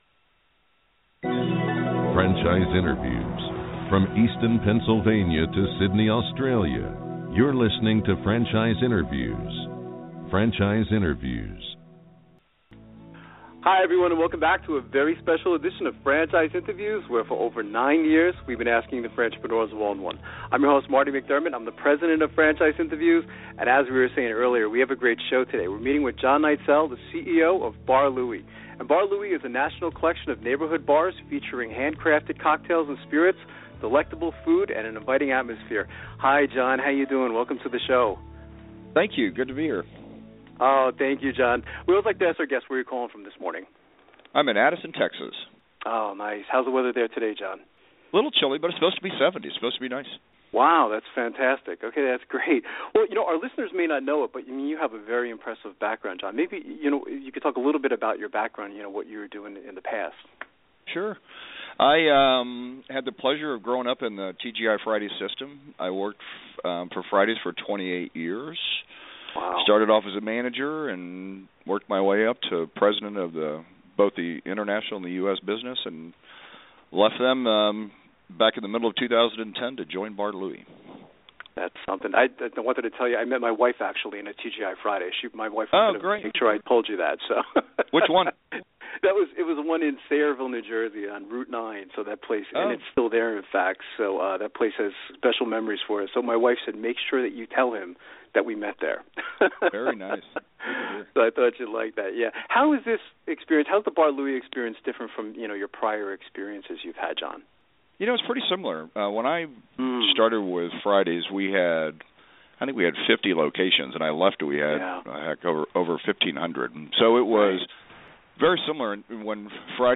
Bar Louie Franchise Interviews meets with the Bar Louie franchise